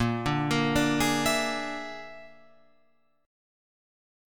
Bb7b5 Chord